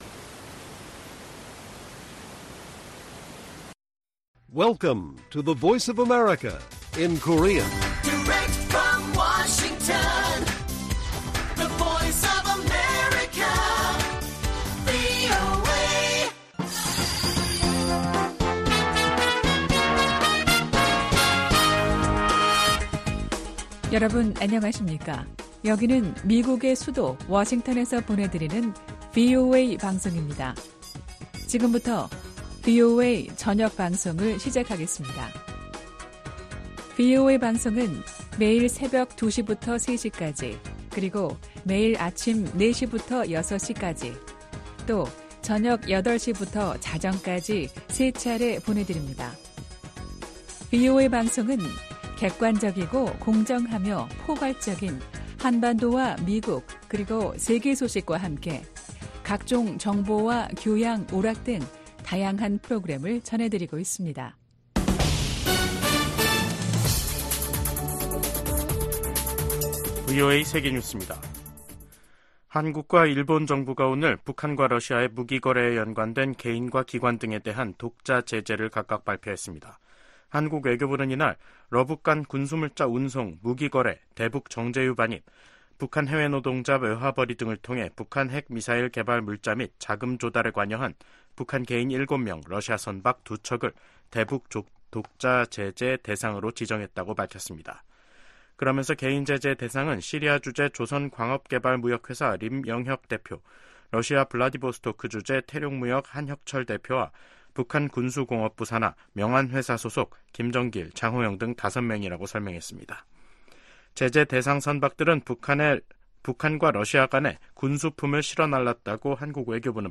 VOA 한국어 간판 뉴스 프로그램 '뉴스 투데이', 2024년 5월 24일 1부 방송입니다. 미 국무부는 미국과 그 동맹들이 역내 긴장을 고조시킨다는 러시아의 주장을 일축하고, 긴장 고조의 원인은 북한에 있다고 반박했습니다. 2025회계연도 미국 국방수권법안이 하원 군사위원회를 통과했습니다. 주한미군 규모를 현 수준으로 유지해야 한다는 내용도 들어있습니다.